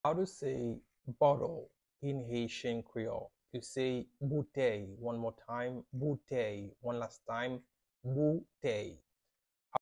“Bottle” in Haitian Creole – “Boutèy” pronunciation by a native Haitian teacher
“Boutèy” Pronunciation in Haitian Creole by a native Haitian can be heard in the audio here or in the video below: